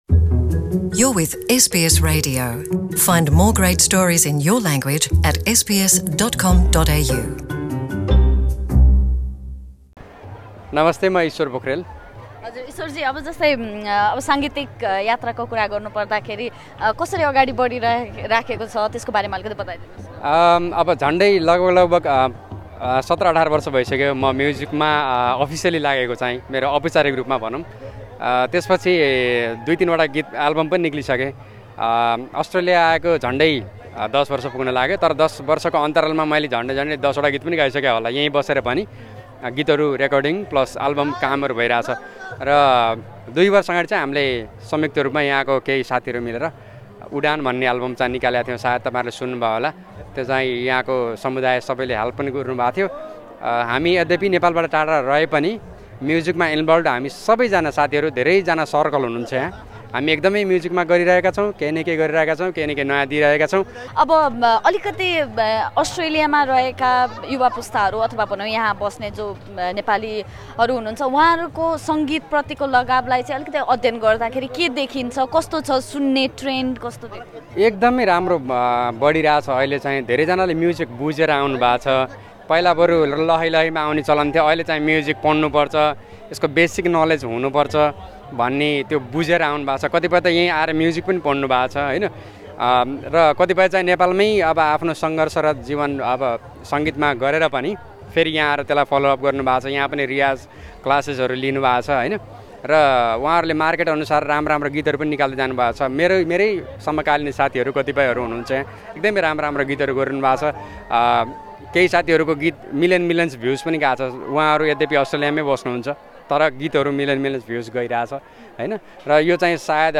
We recently spoke with a few Sydney based Nepali singers on the trends that dominated Nepali music in 2018.